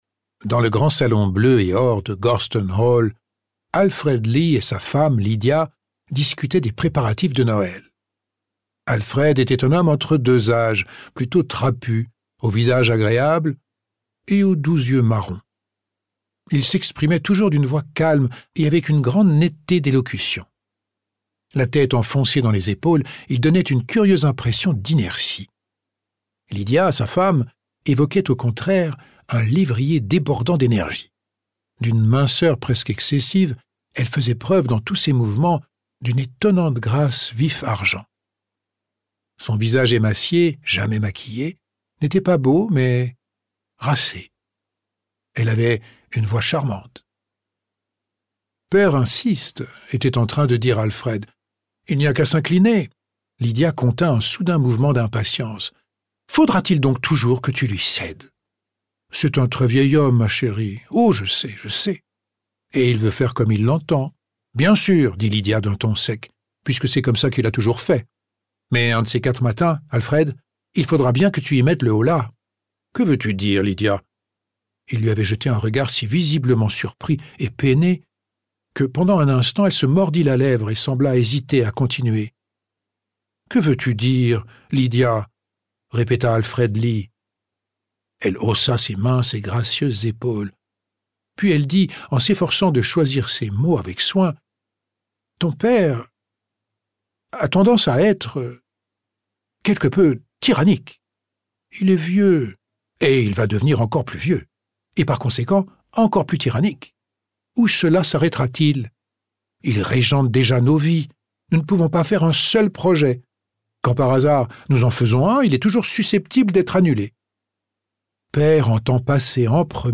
LE-NOEL-DHERCULE-POIROT-EXTRAIT-2MIN.mp3